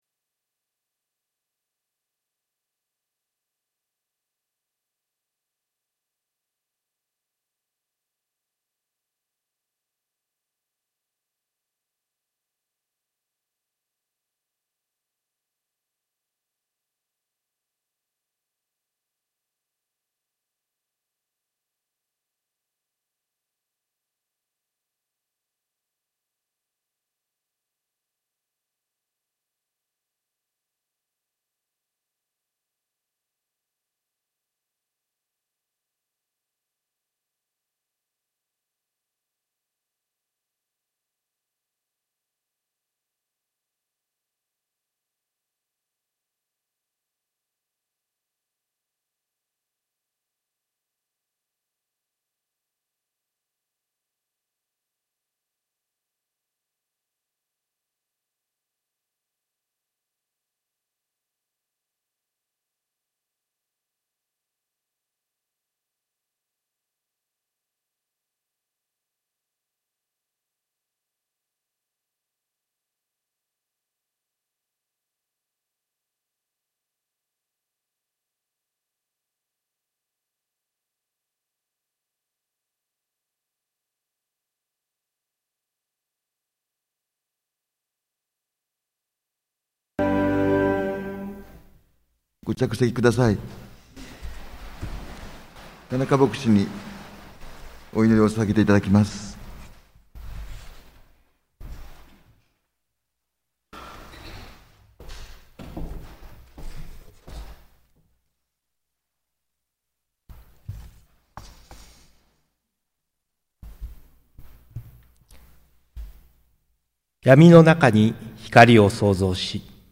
日曜礼拝（音声）